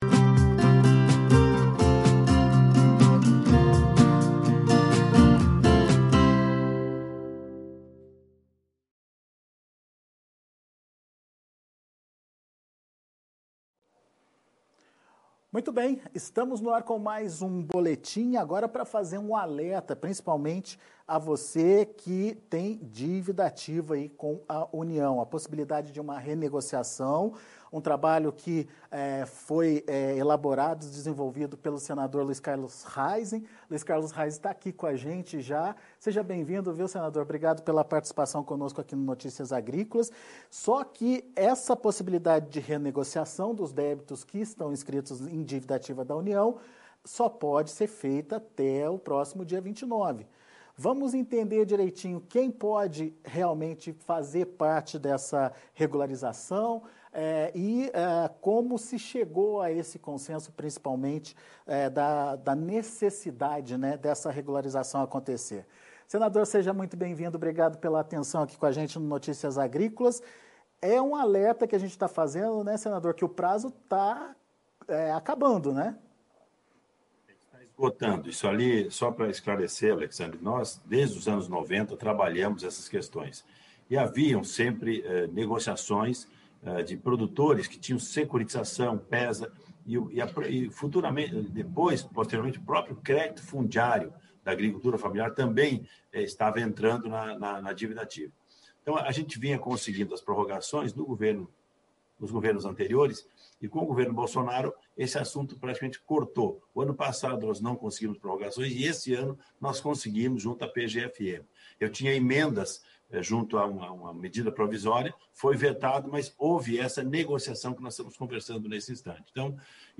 Entrevista com Luis Carlos Heinze - Senador - PP - RS sobre a Dívida ativa da União